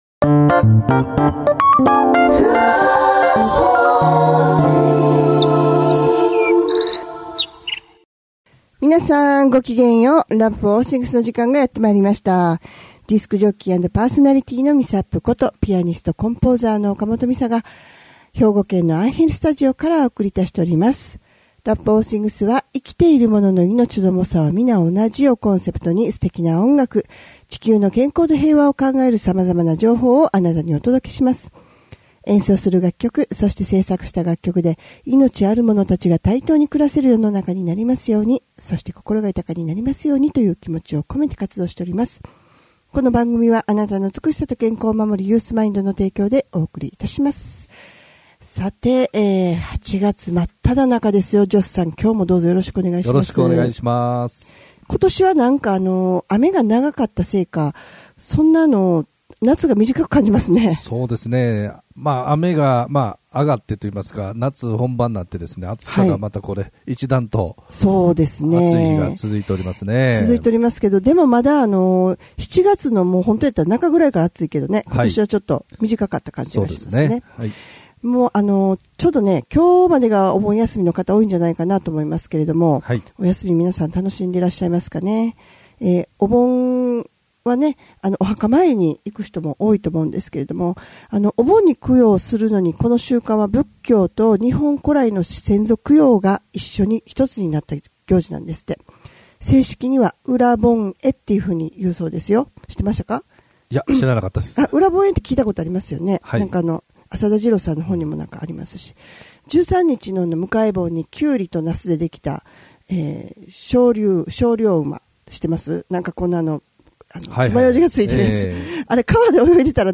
自身の音楽感性を通じ、音楽トークを中心に健康や動物愛護、環境問題など。生きているものの命の重さは同じというコンセプトで音楽とおしゃべりでお送りする番組です♪